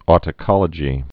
tĭ-kŏlə-jē)